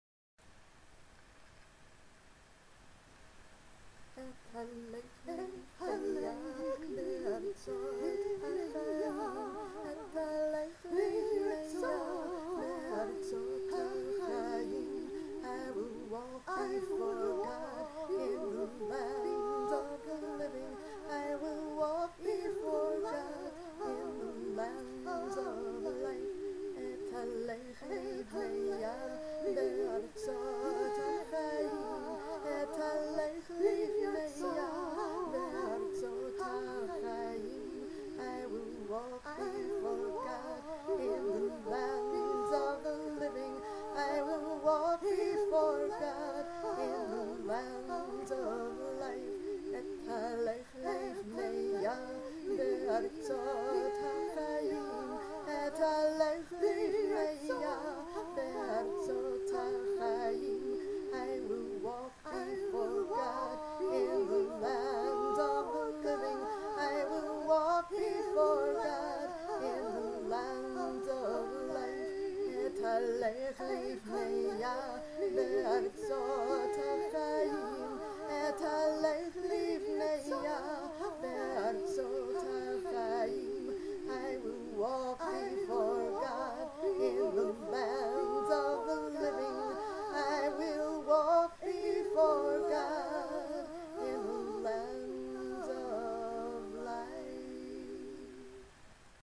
When I chant these compelling words from Psalm 116, I am embodying my belief in God’s loving, life-giving Presence that surrounds me and beckons me towards ever-more radiant vitality.